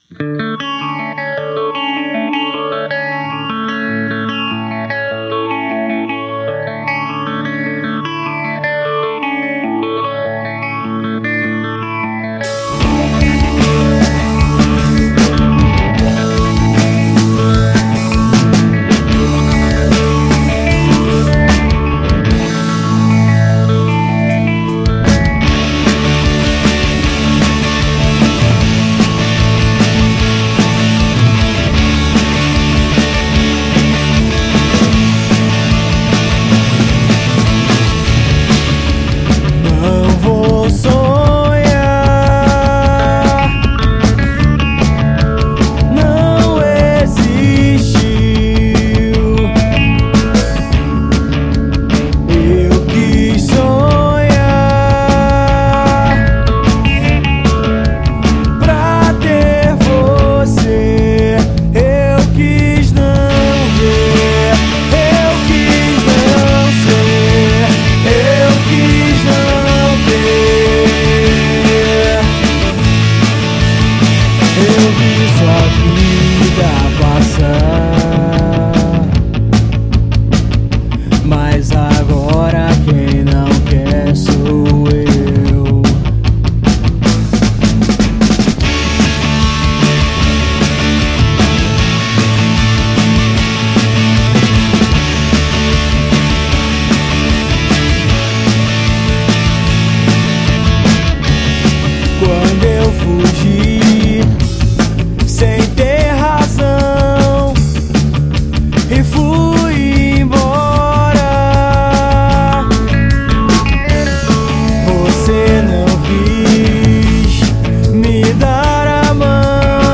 EstiloPunk Rock